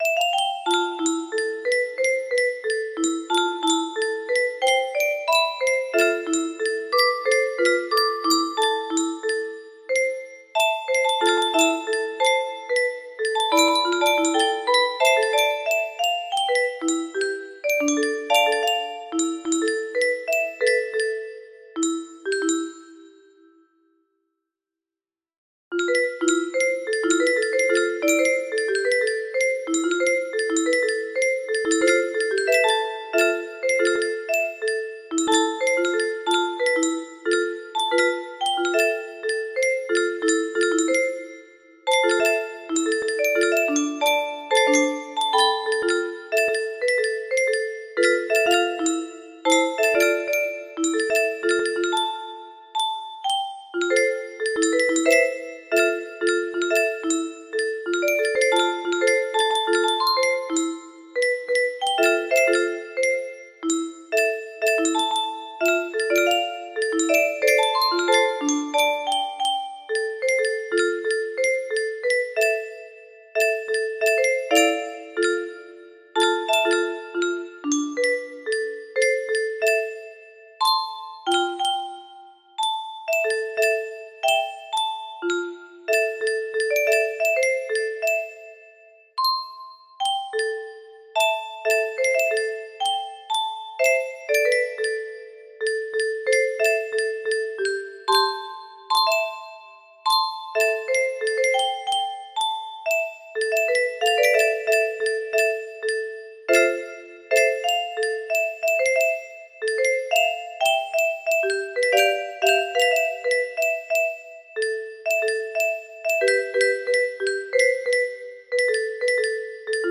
Unknown Artist - Untitled music box melody
Imported from MIDI from imported midi file (27).mid